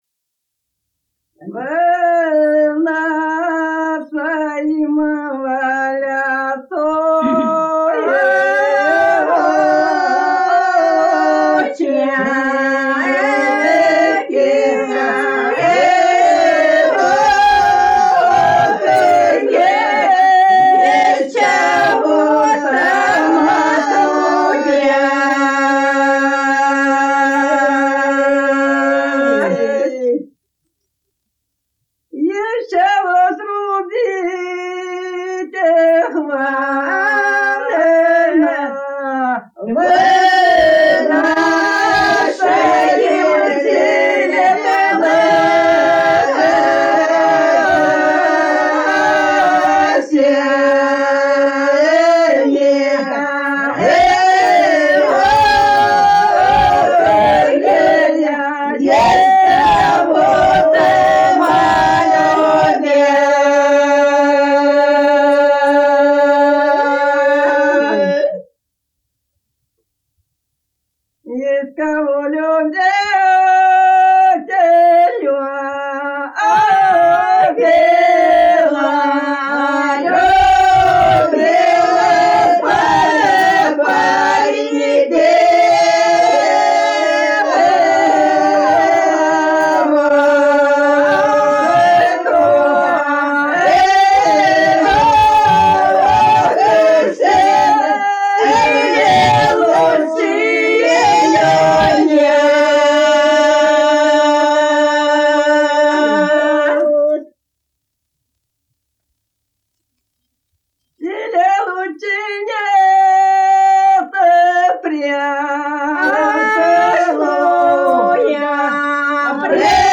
Народные песни Касимовского района Рязанской области «Во нашеим во лясочки», лирическая.